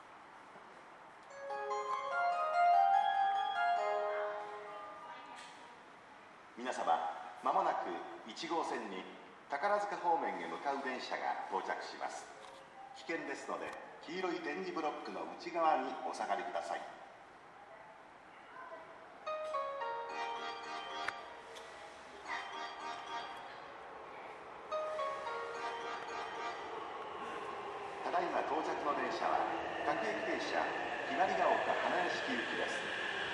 この駅では接近放送が設置されています。
１号線HK：阪急宝塚線
接近放送各駅停車　雲雀丘花屋敷行き接近放送です。